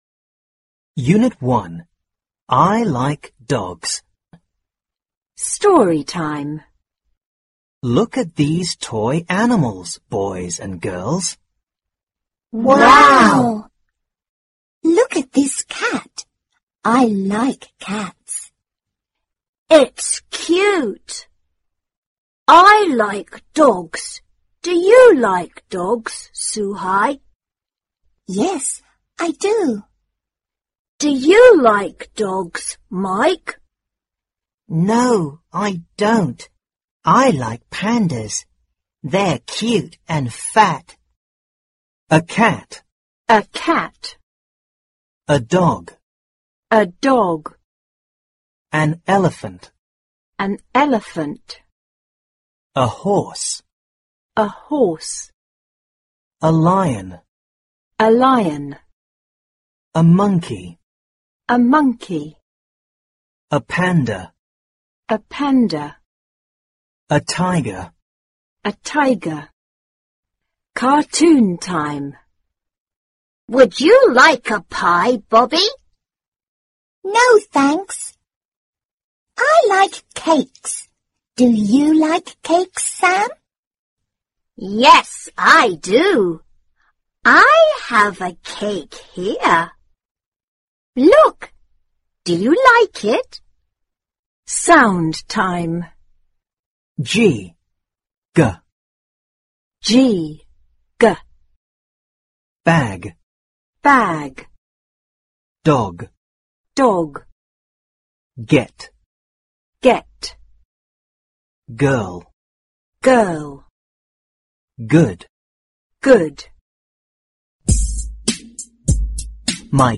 四年级英语上Unit 1 课文.mp3